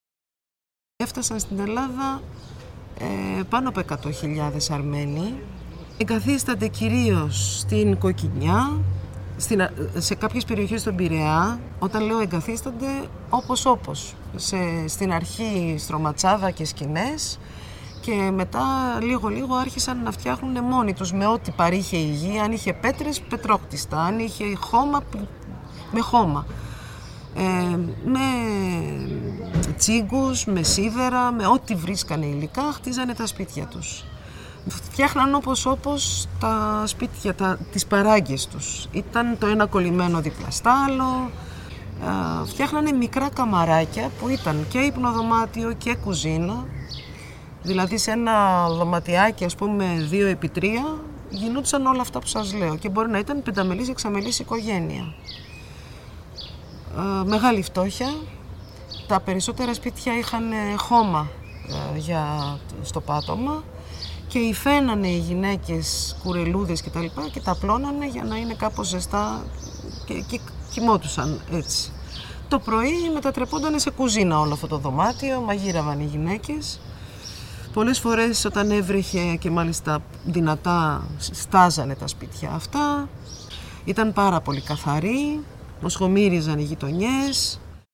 Αποσπάσματα συνέντευξης